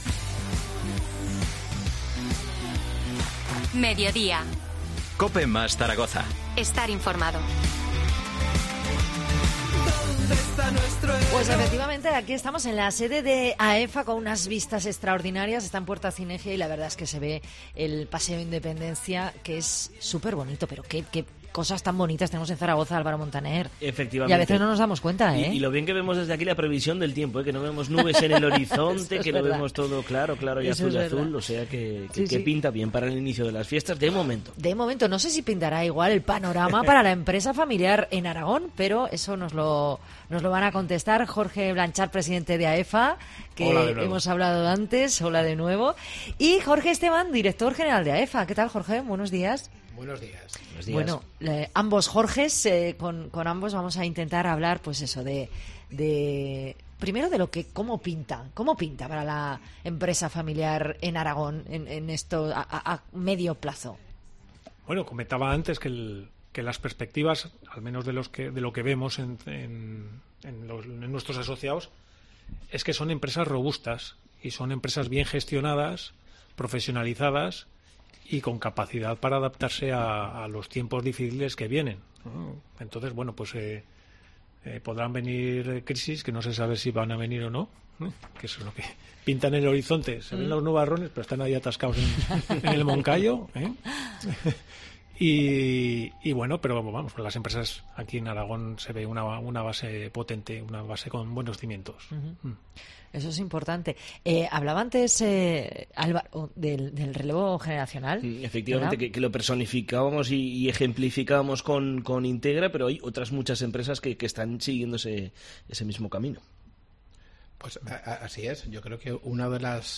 TERTULIA AEFA 5-10-23.